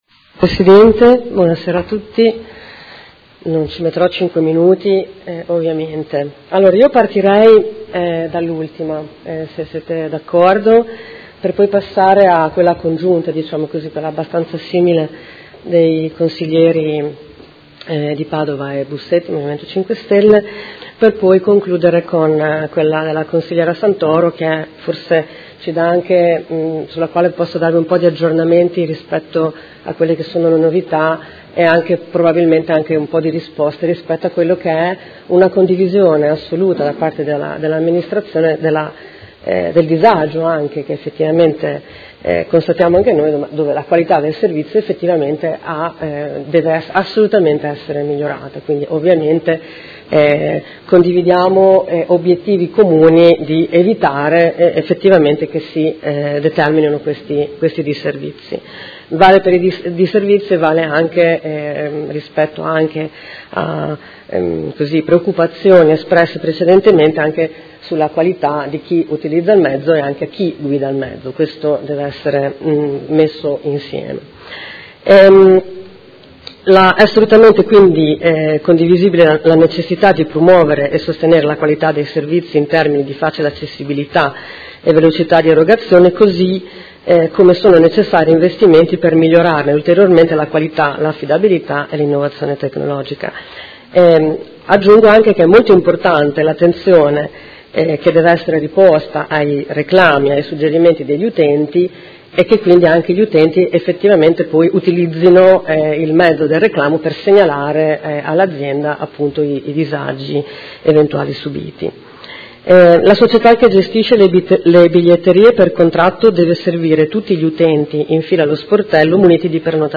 Audio Consiglio Comunale